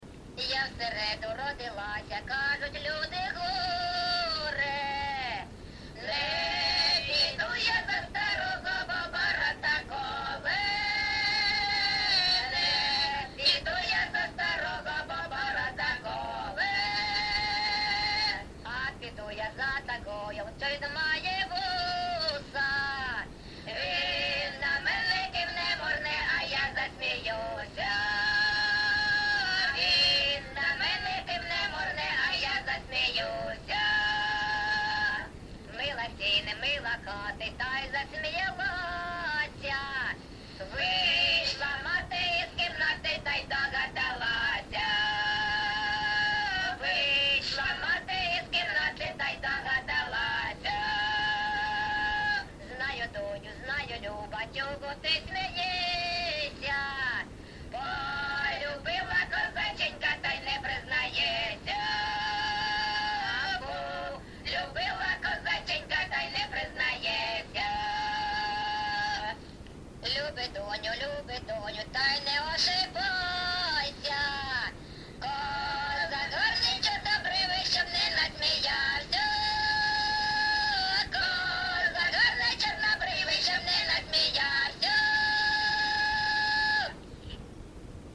ЖанрПісні з особистого та родинного життя, Жартівливі
Місце записус. Некременне, Олександрівський (Краматорський) район, Донецька обл., Україна, Слобожанщина